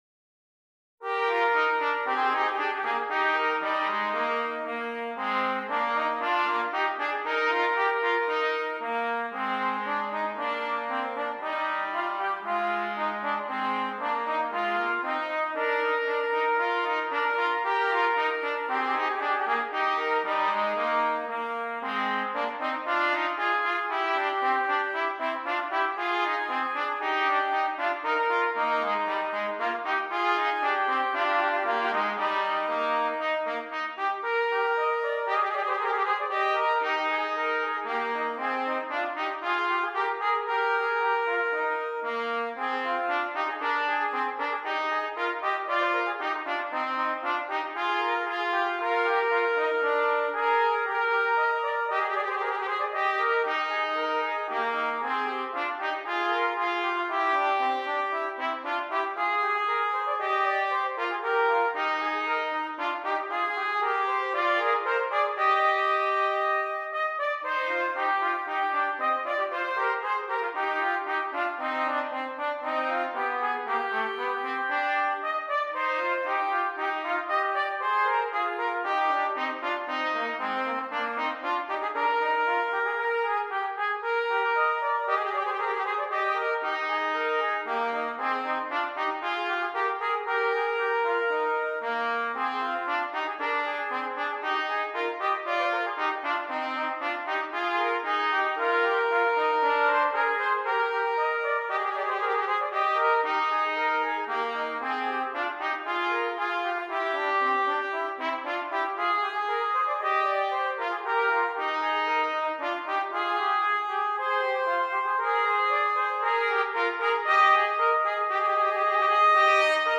Brass
2 Trumpets